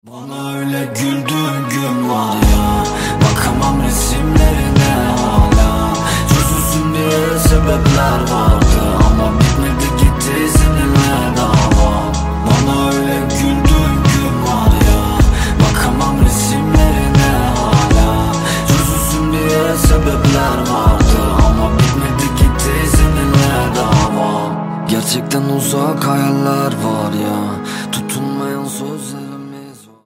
Rap/Hip-Hop